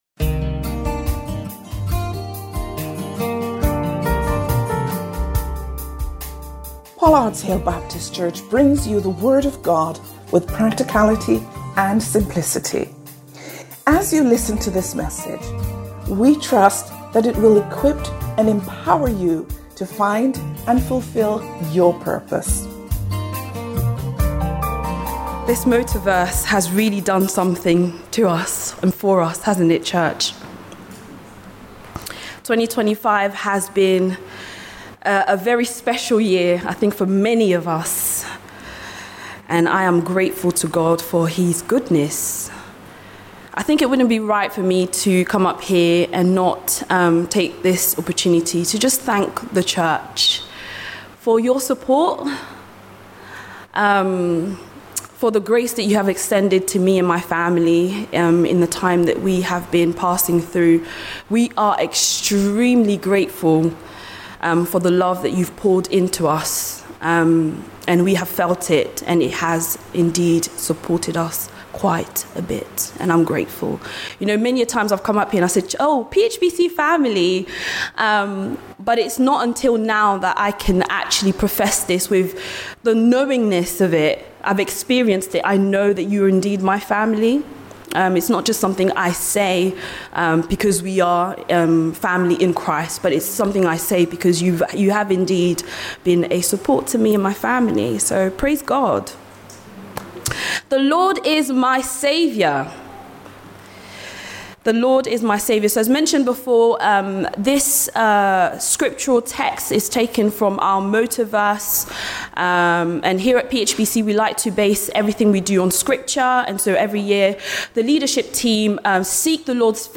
Sermons – Page 10 – Pollards Hill Baptist Church